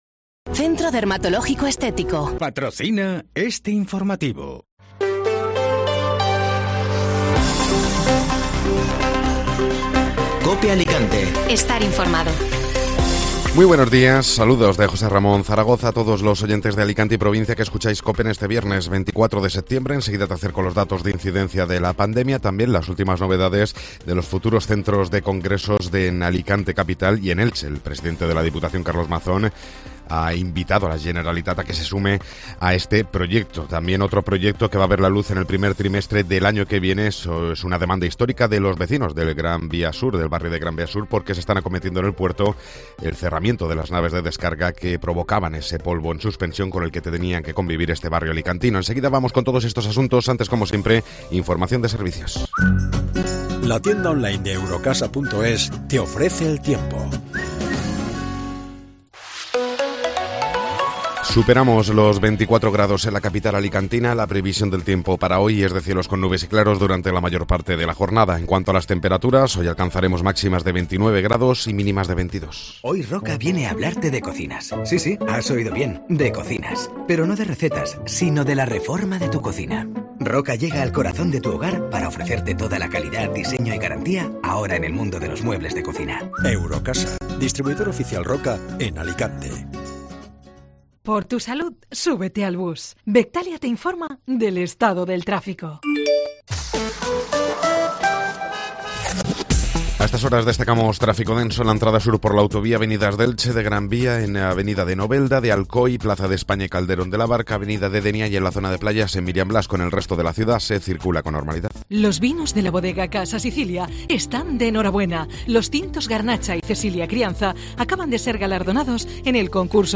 Informativo Matinal (Viernes 24 de Septiembre)